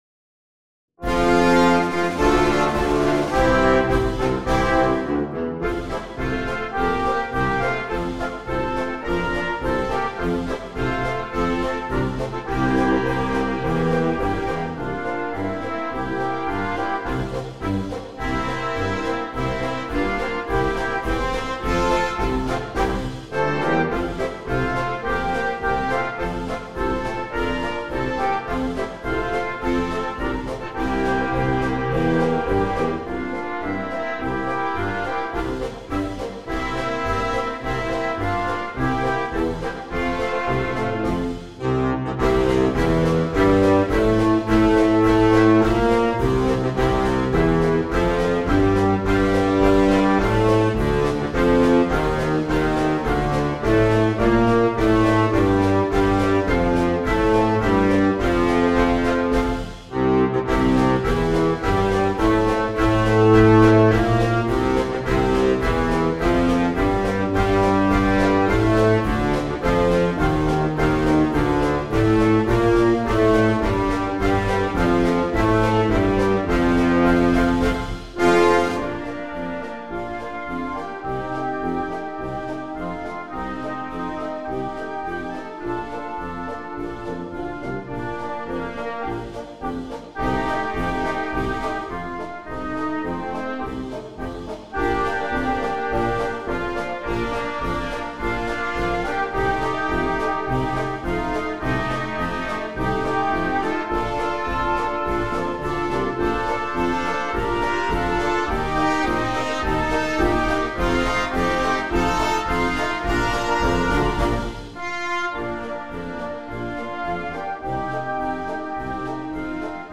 Marsch für Jugendorchester
Besetzung: Blasorchester